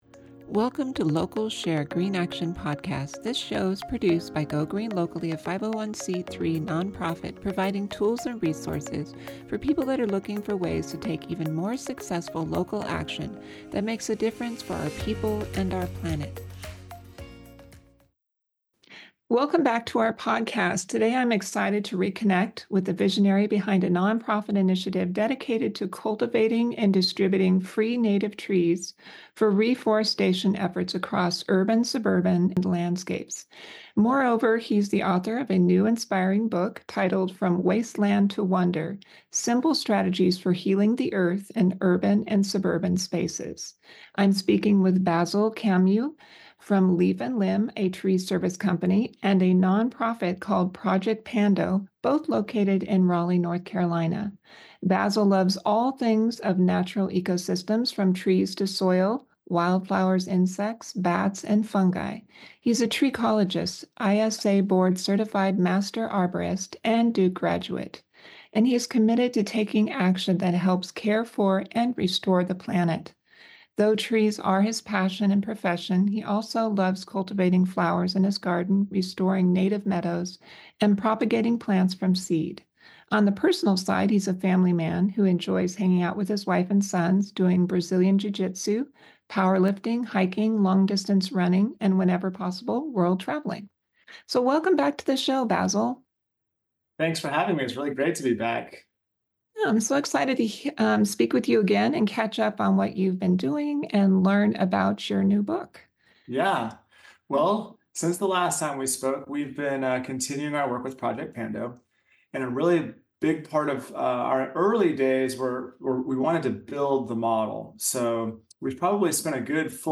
Join us for an engaging discussion on the importance of reforestation, the beauty of native plants, and the simple steps we can all take to heal the planet.